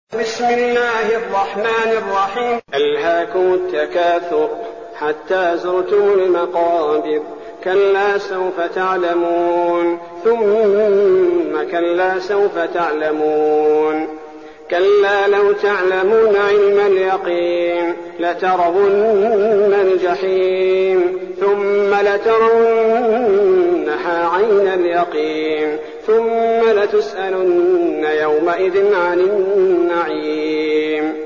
المكان: المسجد النبوي الشيخ: فضيلة الشيخ عبدالباري الثبيتي فضيلة الشيخ عبدالباري الثبيتي التكاثر